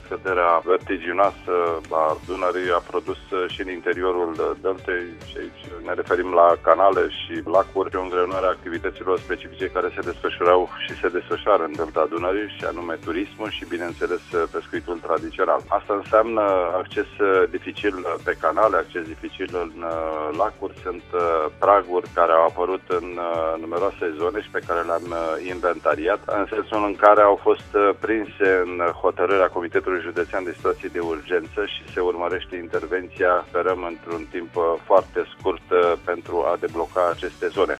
Guvernatorul Rezervaţiei Biosferei Delta Dunării, Lucian Simion, a explicat la Radio România Actualităţi în ce constau efectele nedorite ale scăderii debitului fluviului :